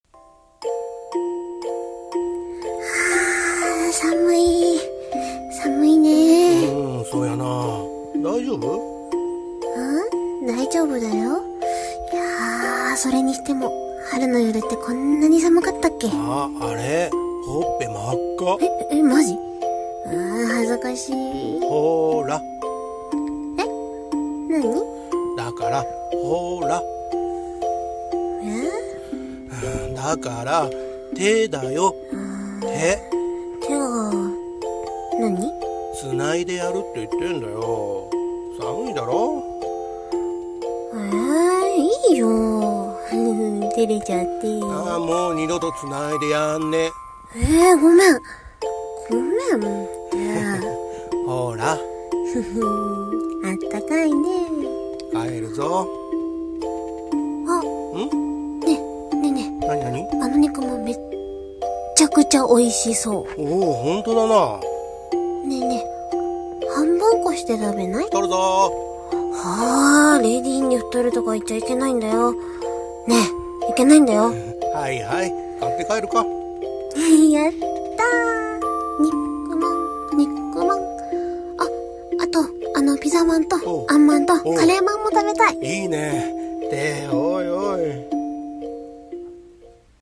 手を繋いで帰ろう / 声劇